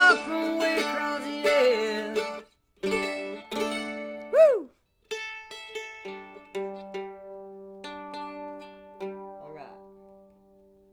(captured from facebook)
01. warm up (0:10)